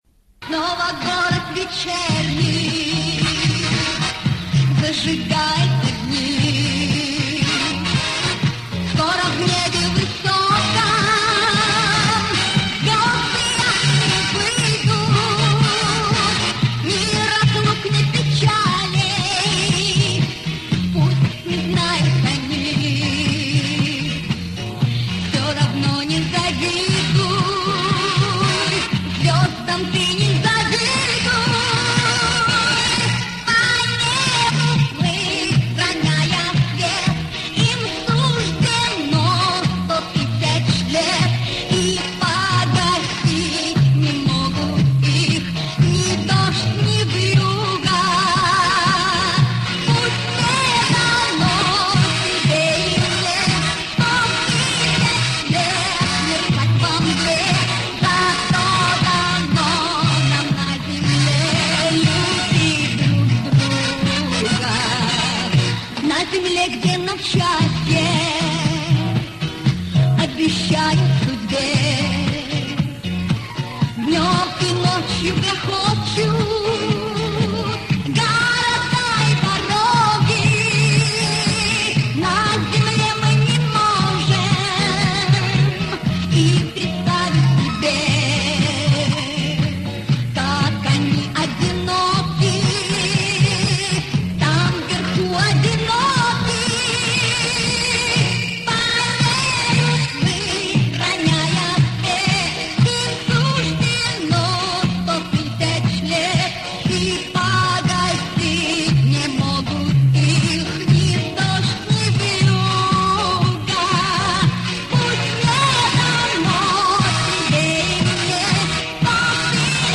А это женское исполнение.